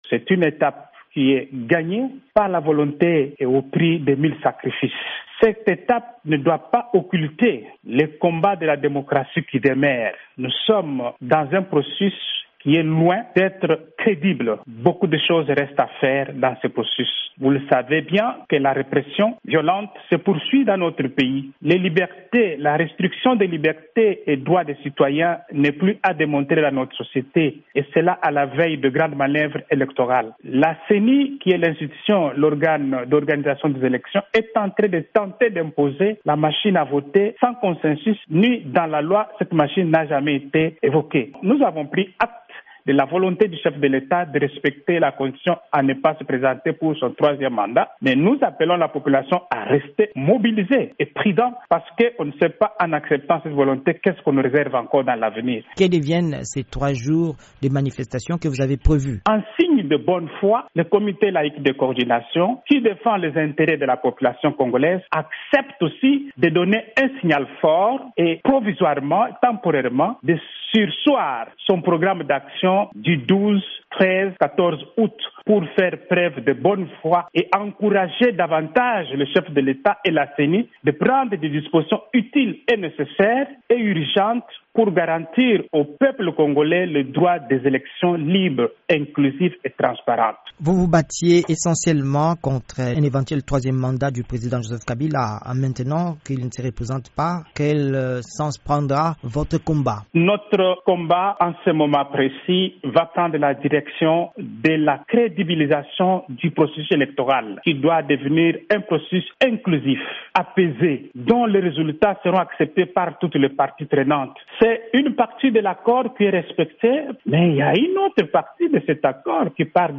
a déclaré dans une interview à VOA Afrique